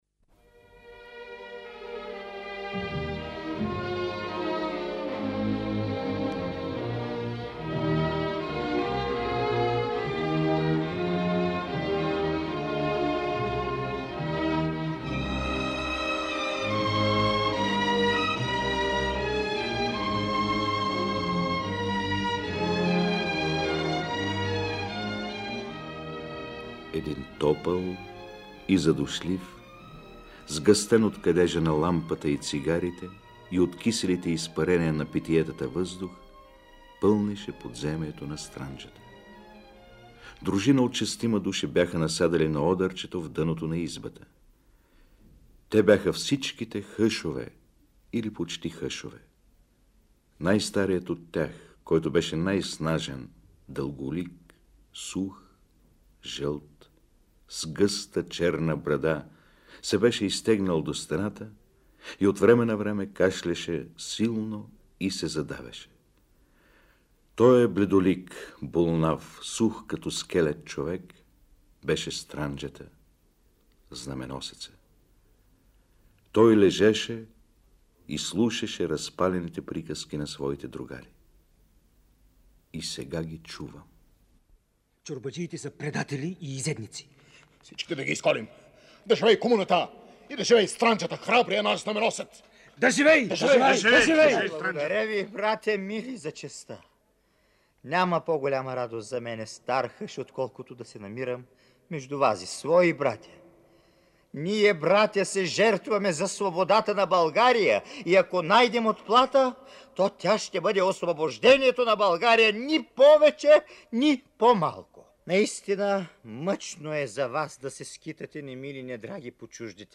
Отличаващия се актьорски маниер на Майноловски откриваме в редица постановки на Радиотеатъра, които се съхраняват в Златния фонд на БНР. Чуйте го в ролята на Странджата в откъс от „Немили-недраги“, излъчен в радиоефира през 1975 година, четец Любомир Кабакчиев: